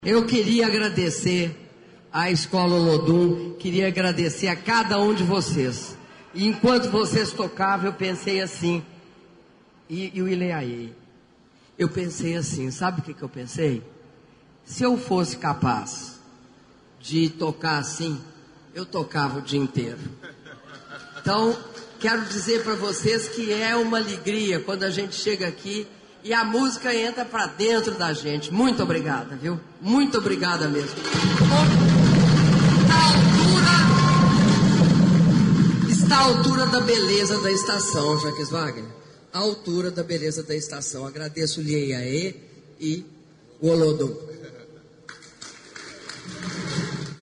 Palavras da Presidenta da República, Dilma Rousseff, após cerimônia de entrada em operação do Sistema Metroviário de Salvador e Lauro de Freitas, trecho Lapa-Acesso Norte - Salvador/BA (47s) — Biblioteca